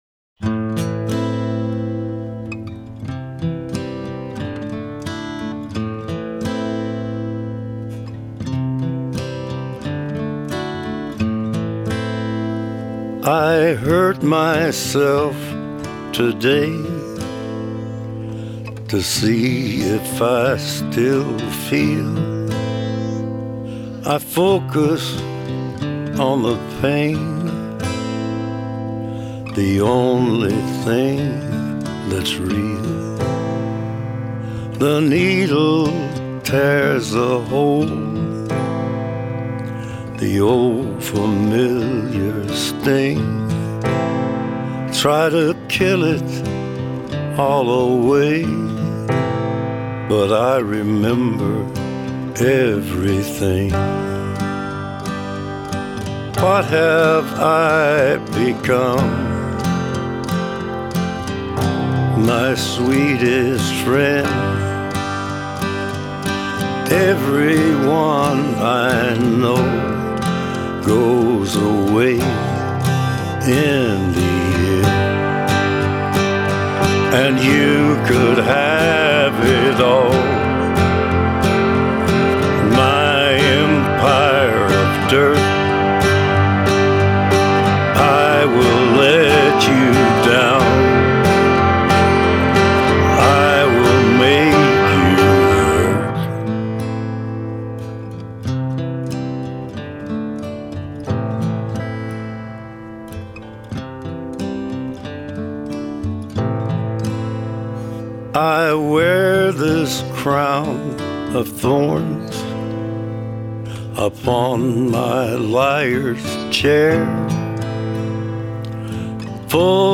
Folk country Rock